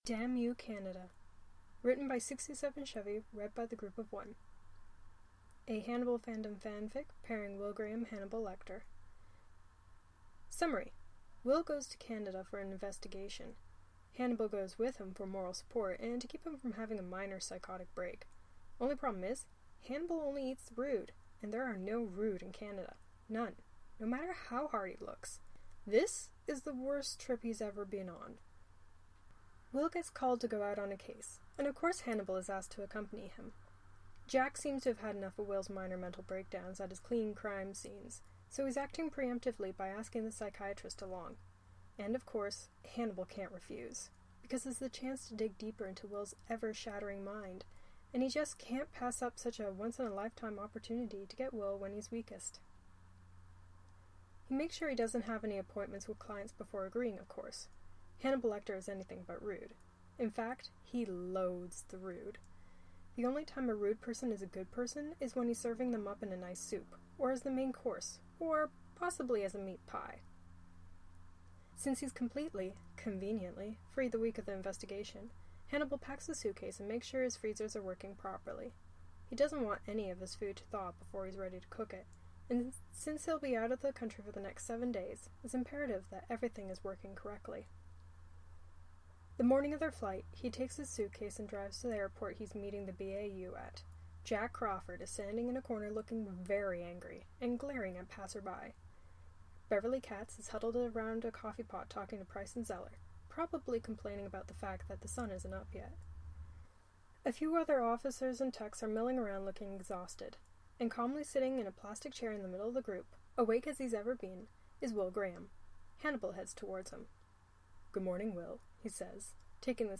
Damn You, Canada: the Podfic